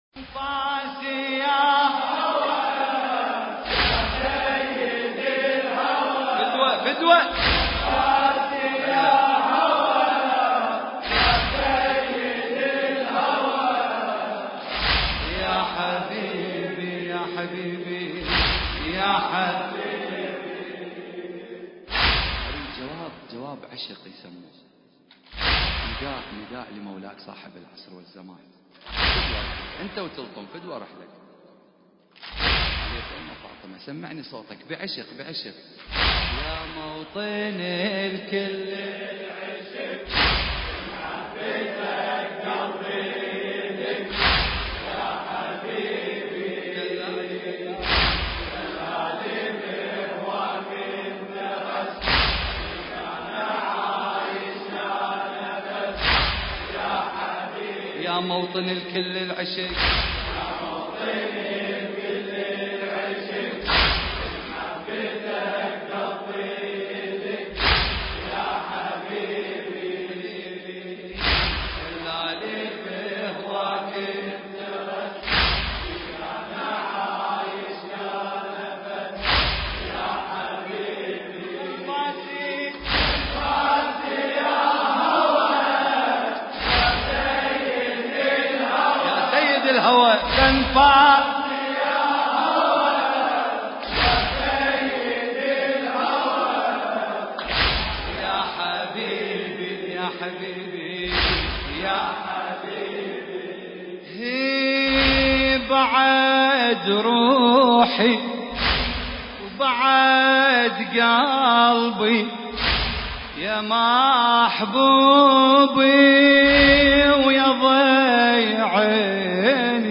المكان: حسينية قصر الزهراء (عليها السلام) – هولندا